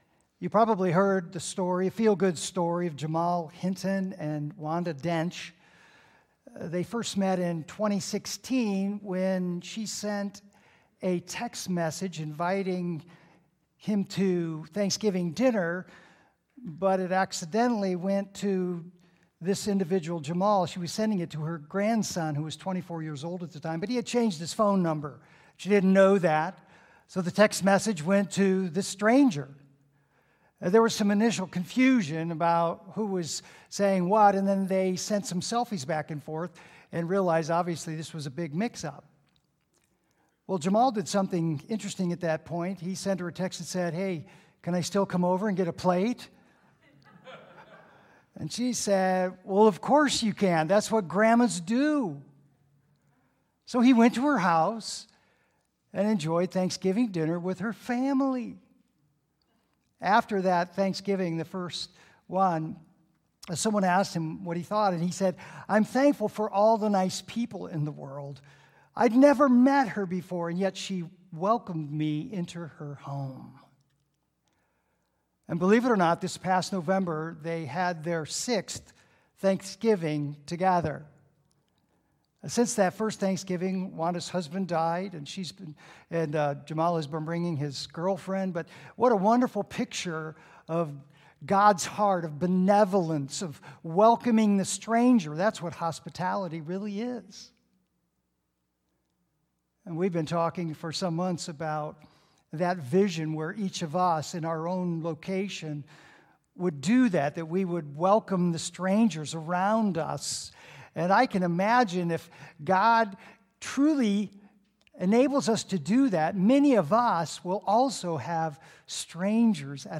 Previous Sermons 40 Days of Prayer - Reawakening to the Mission of Christ